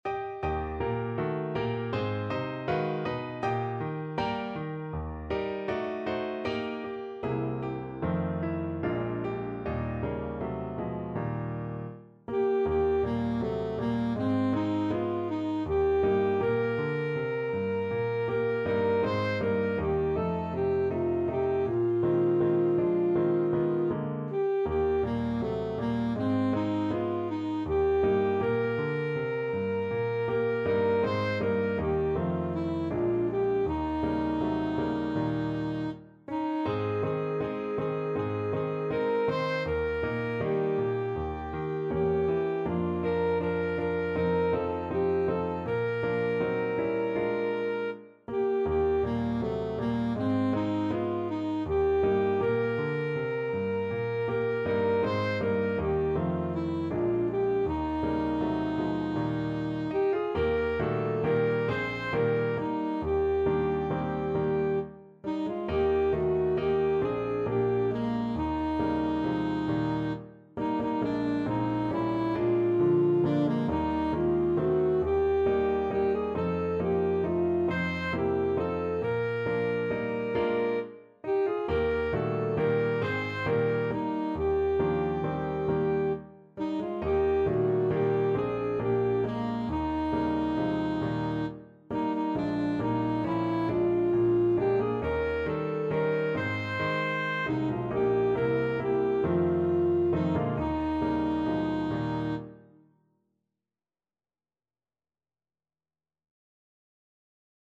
Alto Saxophone
4/4 (View more 4/4 Music)
Eb major (Sounding Pitch) C major (Alto Saxophone in Eb) (View more Eb major Music for Saxophone )
Andante